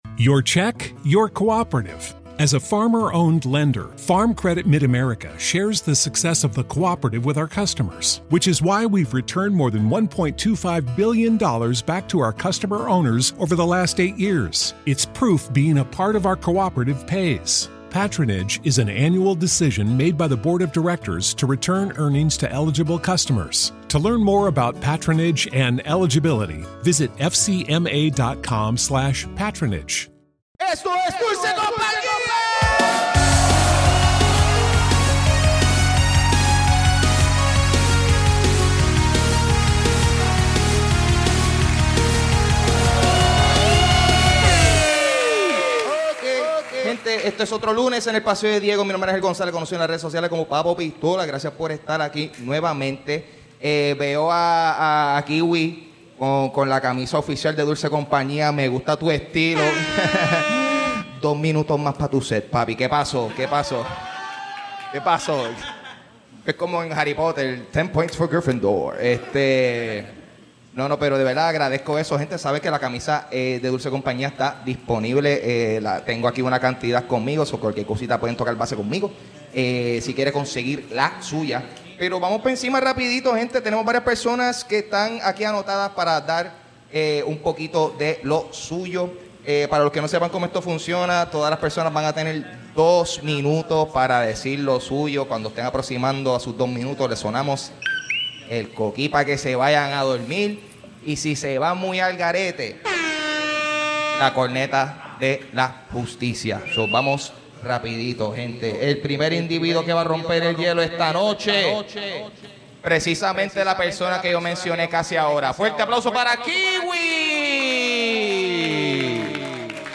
Nos visita un comediante de fuera de la isla y hace stand up en inglés.